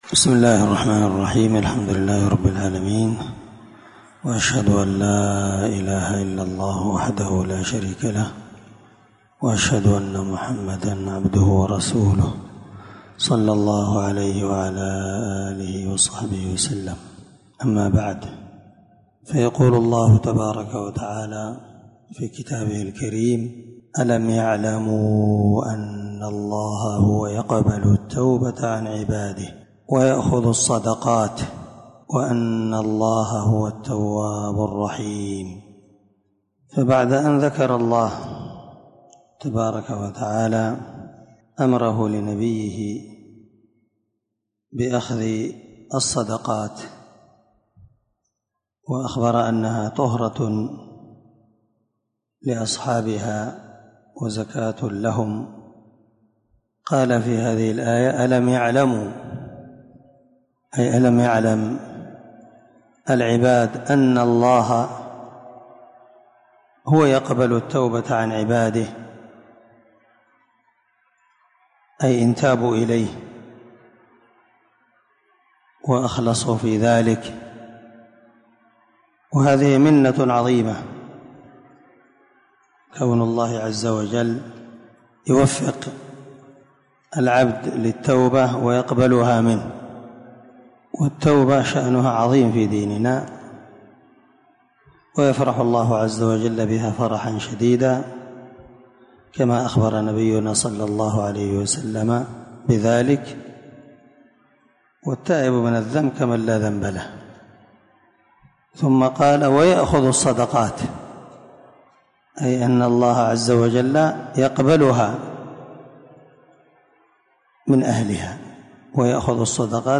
573الدرس44تفسير آية ( 104_106) من سورة التوبة من تفسير القران الكريم مع قراءة لتفسير السعدي